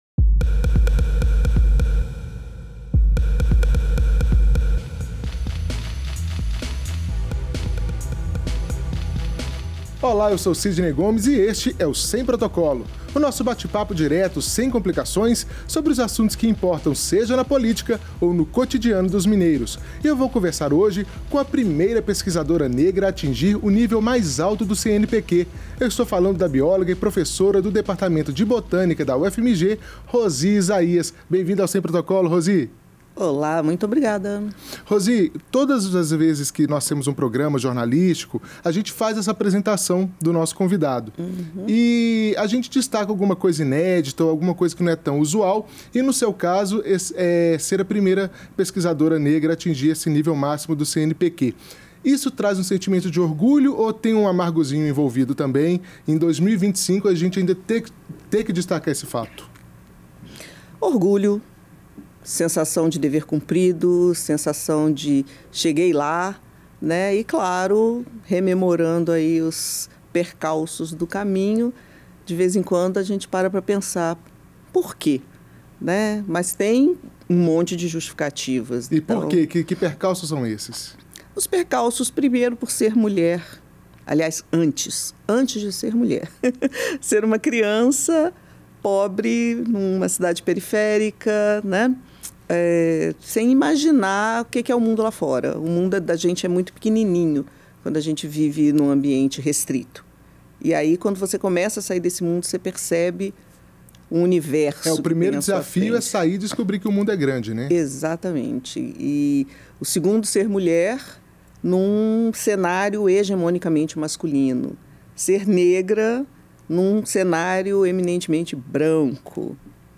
Nesse bate-papo, a pesquisadora 1-A do CNPq revela o ambiente competitivo da academia e fala da mudança de cara da universidade com a política de cotas.